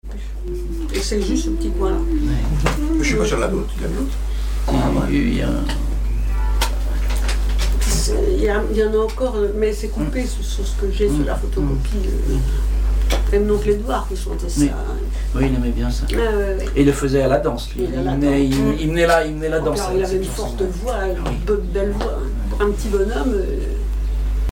Chansons et commentaires
Catégorie Témoignage